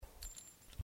bulletshells03.mp3